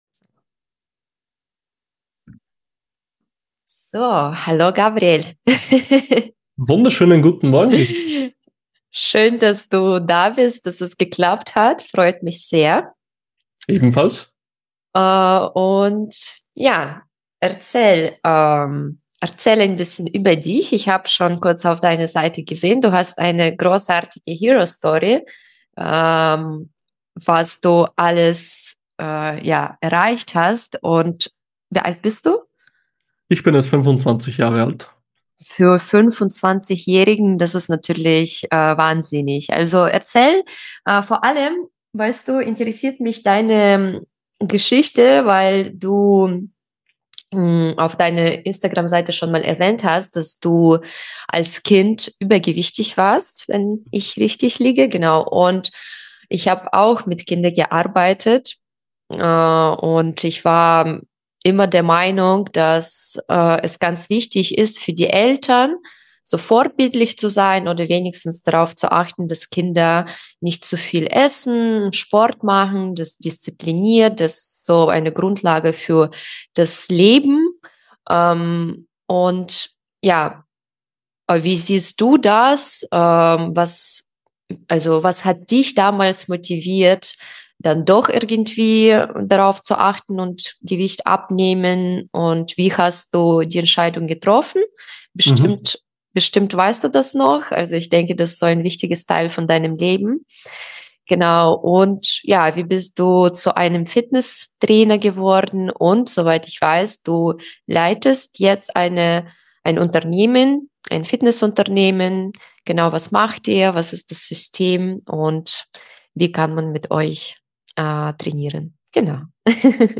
Dieses Gespräch geht weit über Fitness hinaus. Es geht um Identität, Durchhalten, Rückschläge, Muster im Leben – und darum, warum Erfolg selten Zufall ist.